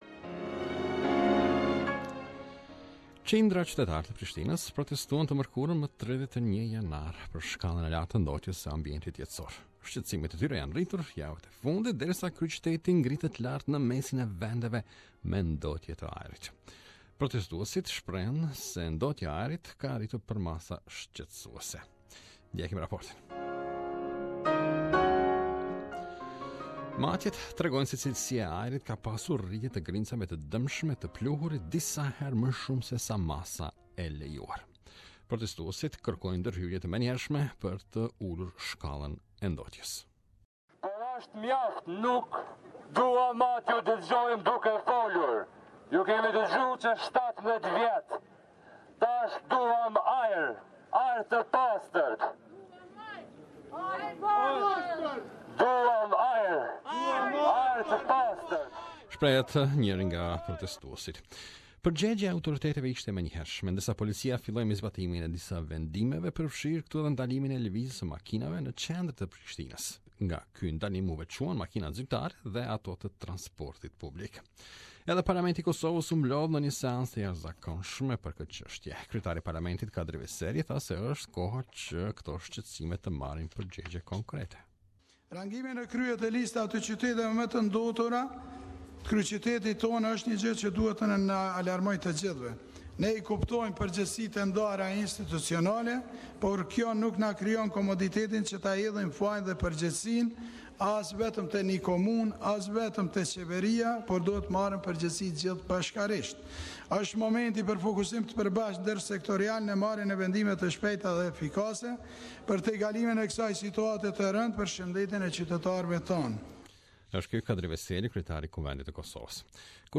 Protest organizers played a sound of a person breathing deeply. The crowd chanted Dont poison us, Help and Lets save Pristina.